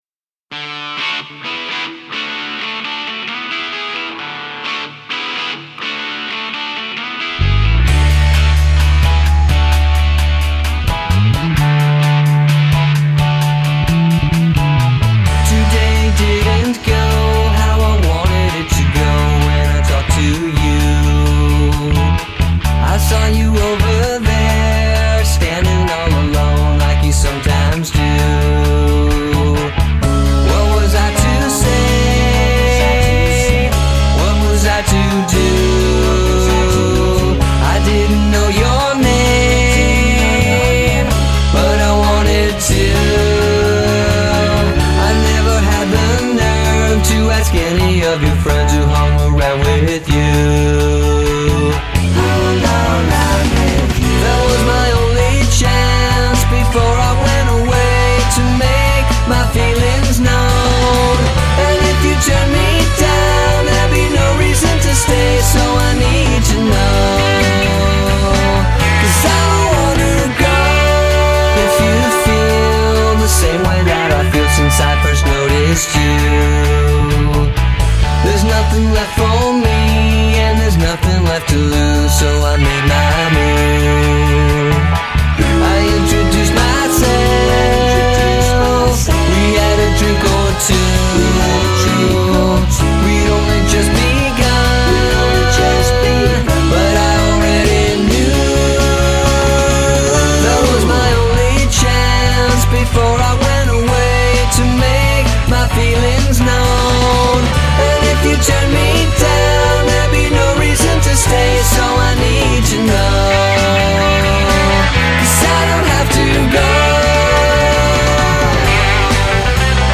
is a huge Power Pop fan and has been writing